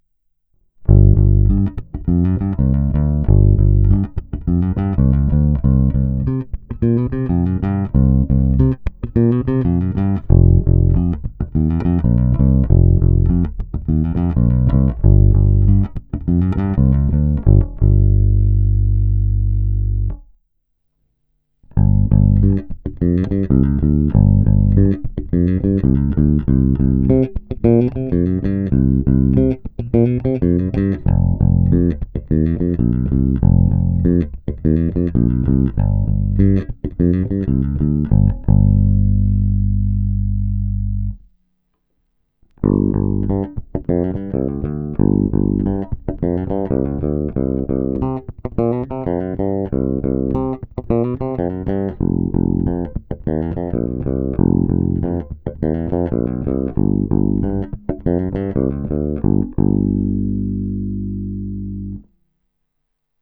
Zvuk je standardní, očekávatelný při dané koncepci, velice dobře hraje i struna H, a to jsem použil dokonce vintage typ hlazených strun.
Není-li uvedeno jinak, následující nahrávky jsou provedeny rovnou do zvukové karty, jen normalizovány, jinak ponechány bez úprav.